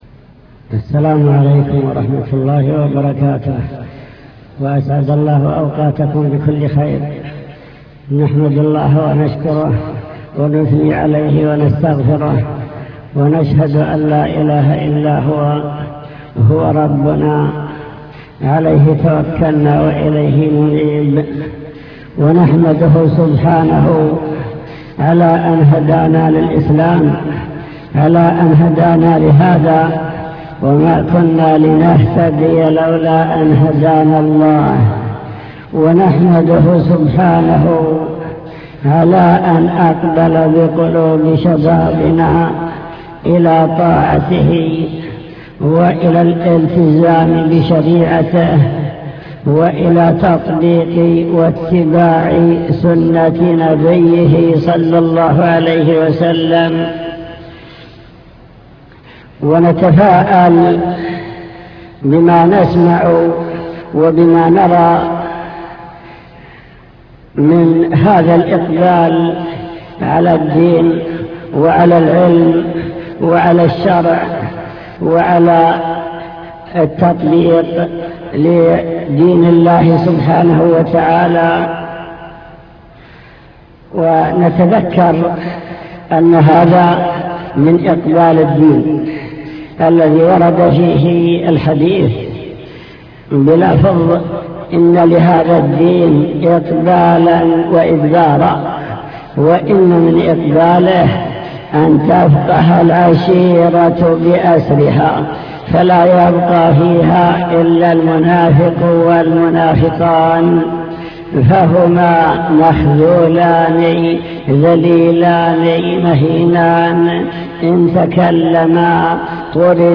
المكتبة الصوتية  تسجيلات - محاضرات ودروس  محاضرة في حقيقة الالتزام